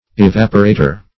Evaporator \E*vap"o*ra`tor\, n.